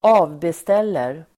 Uttal: [²'a:vbestel:er]